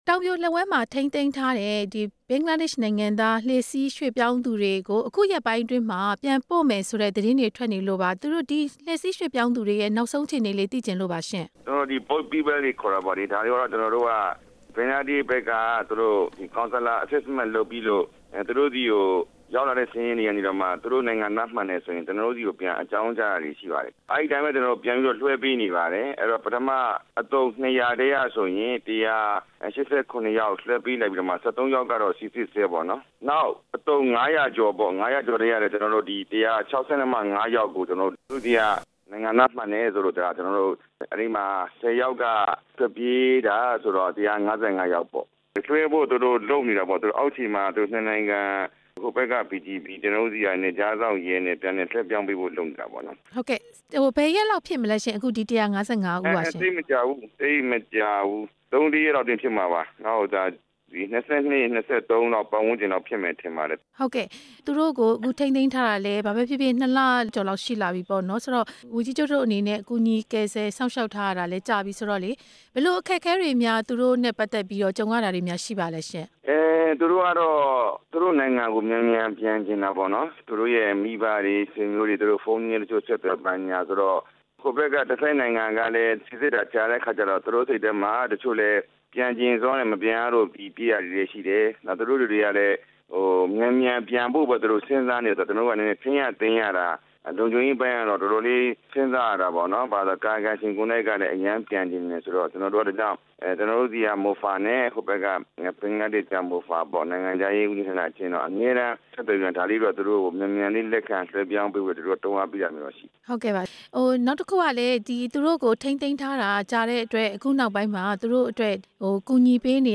လှေစီးဒုက္ခသည်တွေ ပြန်ပို့မယ့် ကိစ္စ ဝန်ကြီး ဦးမောင်မောင်အုန်း ကို မေးမြန်းချက်